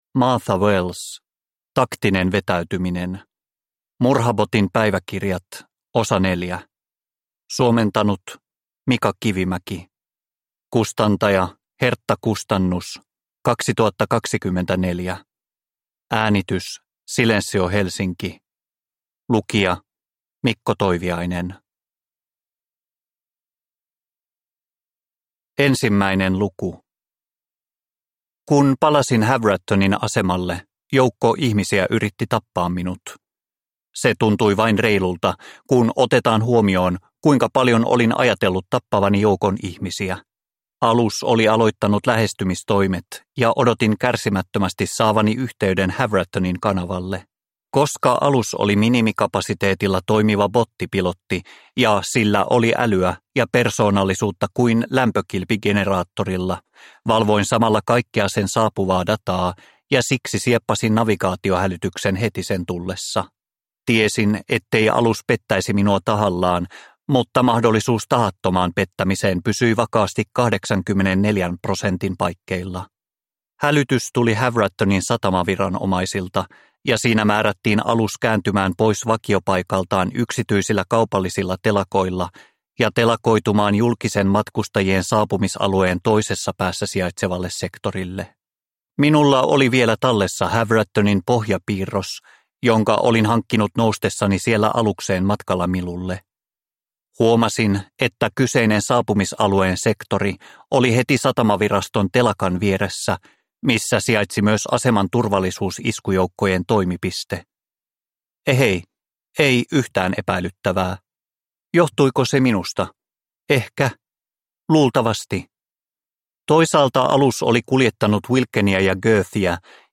Taktinen vetäytyminen – Ljudbok